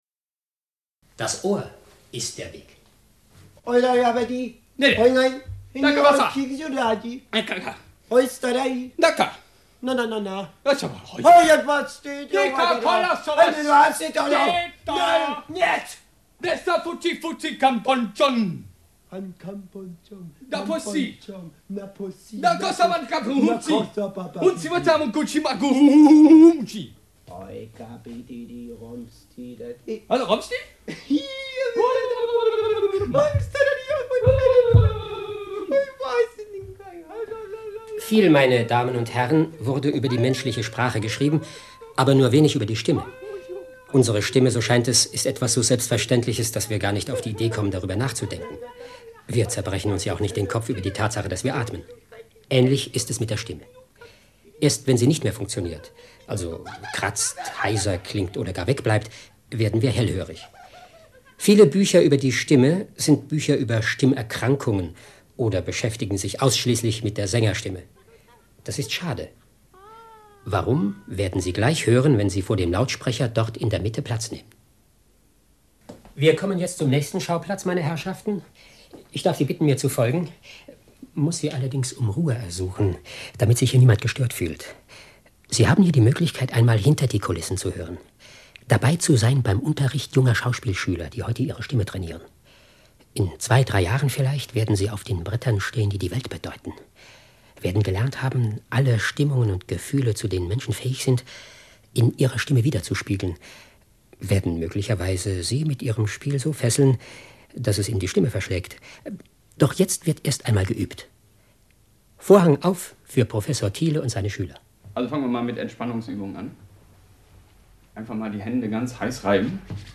Führung durch ein Kabinet der menschlichen Stimme, Funkfeature, Hessischer Rundfunk 1989, Erstsendung 11.